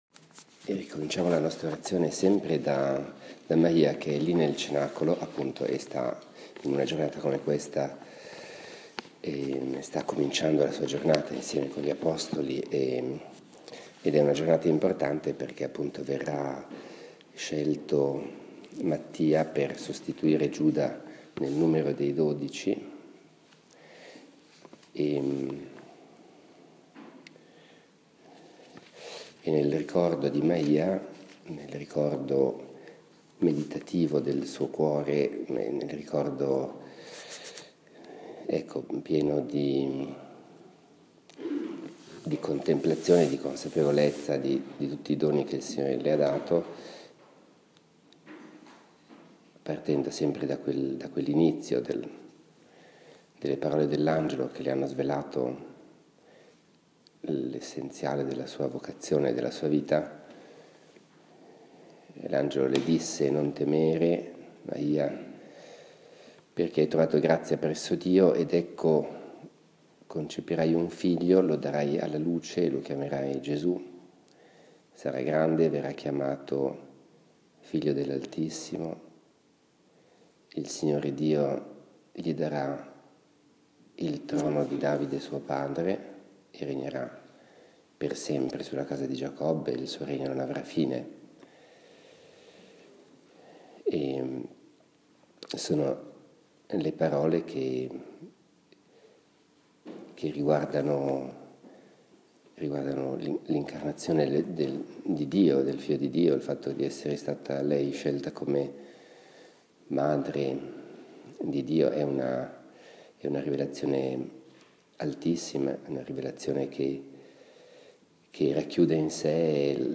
Per questo propongo la sesta meditazione del corso di ritiro del maggio 2016 su Maria.
Questa sul mistero dell’Incarnazione e la maternità divina di Maria, avveniva la mattina del 14 maggio, san Mattia, prima della messa.